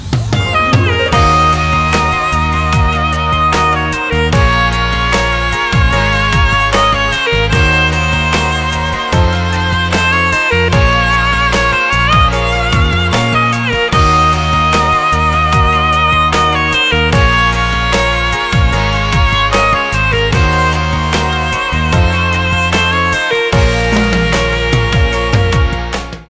• Качество: 320, Stereo
спокойные
без слов
скрипка
Спокойный шансон рингтон с мелодией на скрипке